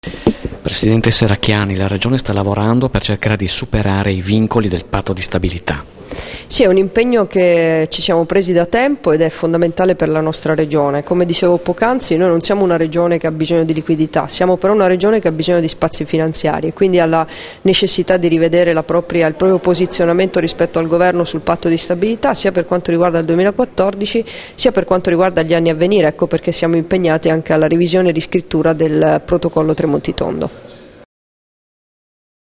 Dichiarazioni di Debora Serracchiani (Formato MP3) [520KB]
rilasciate a margine del convegno "Patto di stabilità: ricadute e prospettive per appalti di lavori e servizi", nella Sala Maggiore della Camera di Commercio a Trieste il 23 giugno 2014